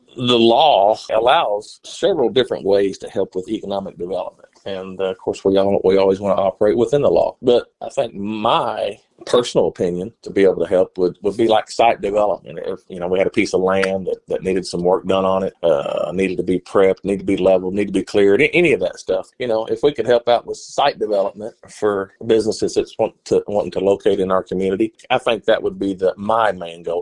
County Judge Kevin Litty, spoke with KTLO News to discuss the parameters of the fund.